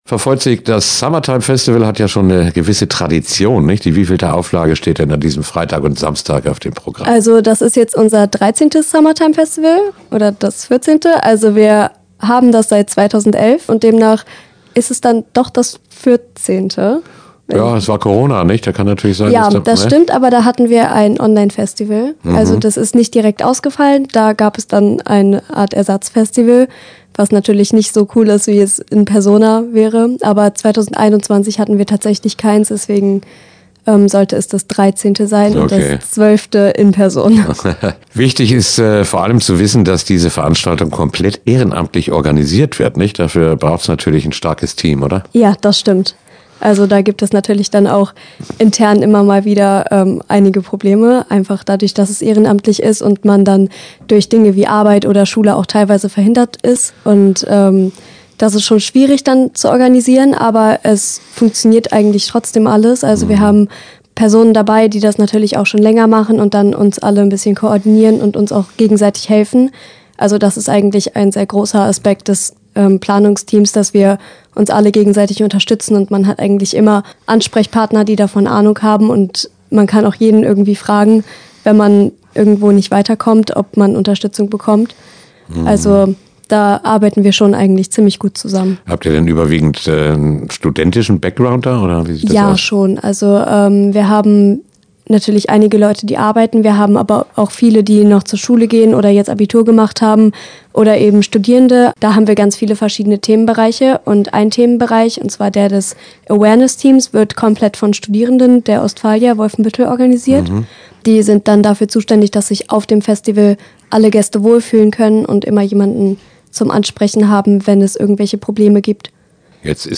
Interview-Summertime-Festival-WF-2024.mp3